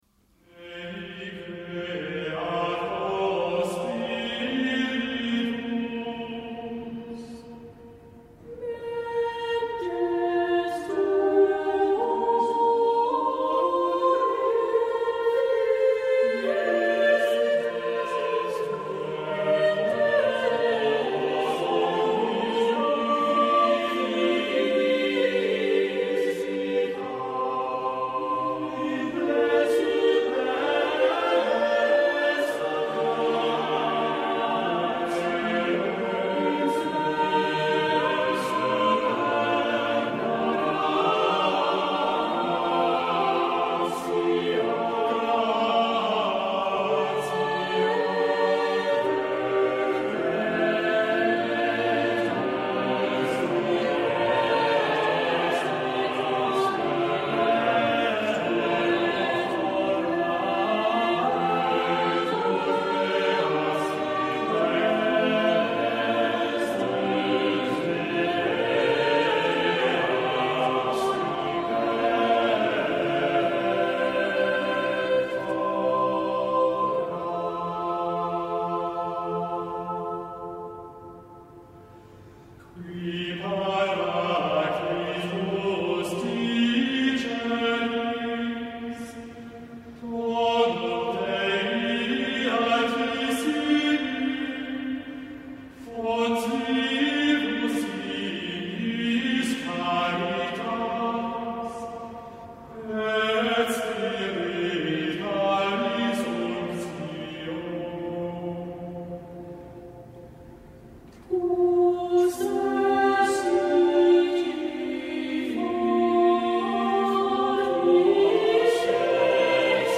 Opening van deze zondag met muziek, rechtstreeks vanuit onze studio.